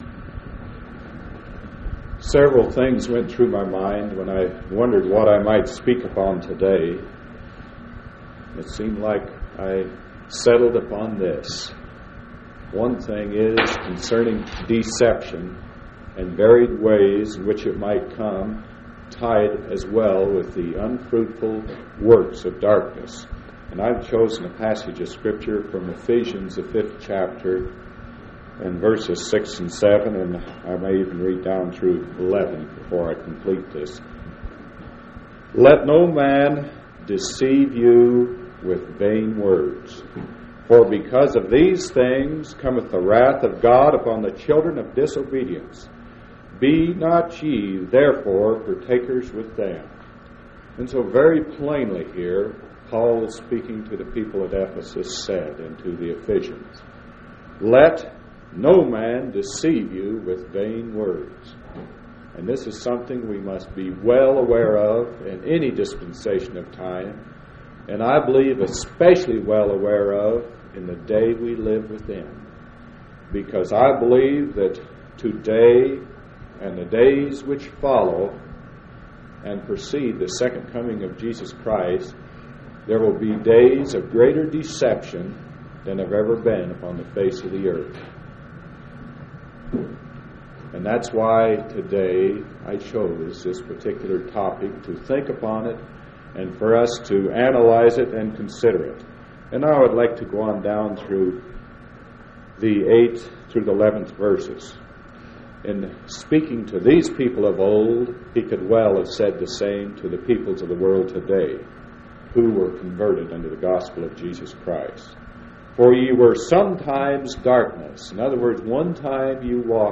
4/17/1977 Location: Grand Junction Local Event